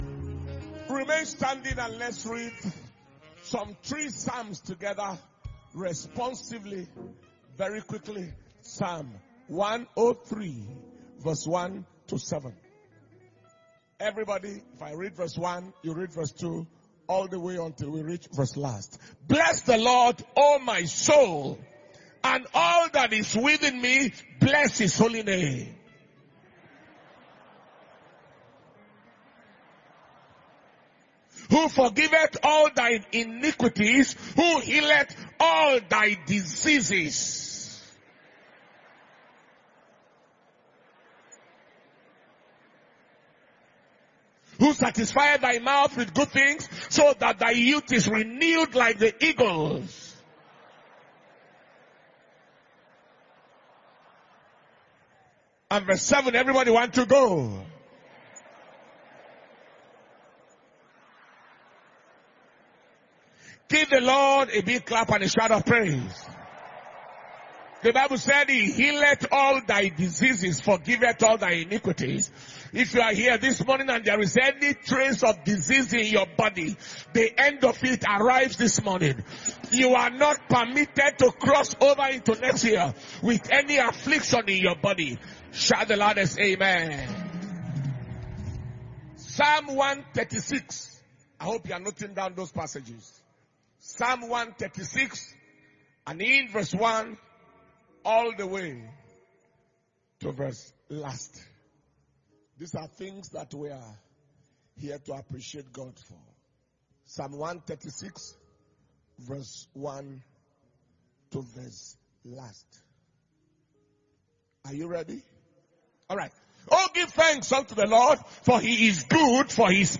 Sunday Combined Service – Sunday, 26th December 2021